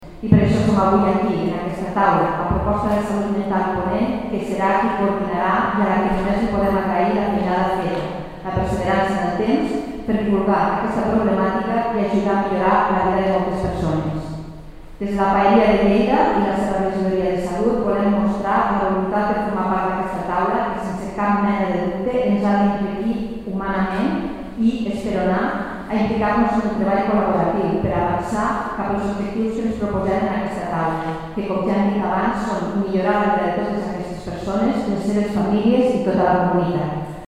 tall-de-veu-de-la-tinent-dalcalde-montse-pifarre-sobre-la-taula-de-salut-mental-de-lleida-i-el-segria